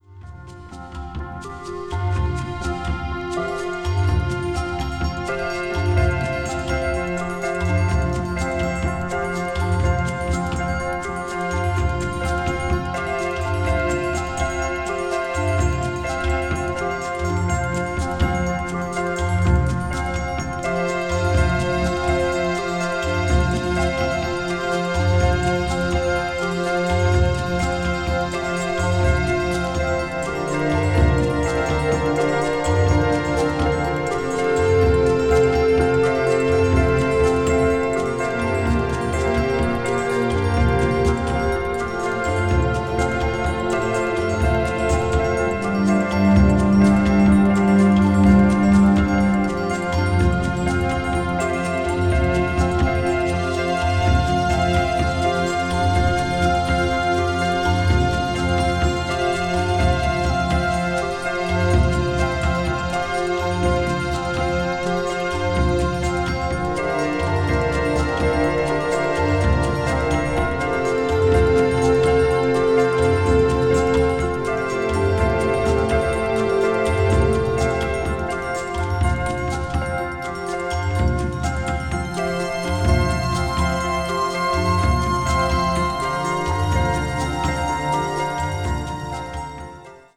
A面にセンターホールが少しずれているため、音にわずかな揺れがありますので、あらかじめご了承のうえお買い求めください。